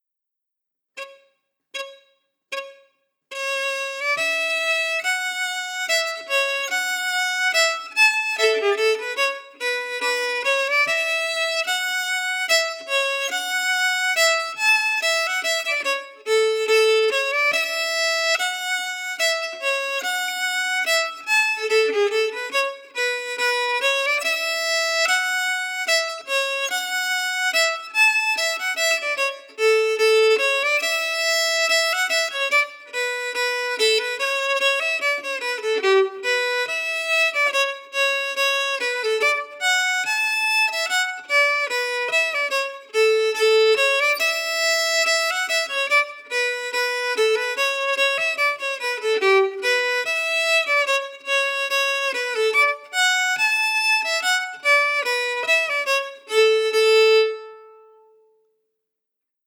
Key: A
Form: March
Slow for learning
Genre/Style: Scottish (pipe) March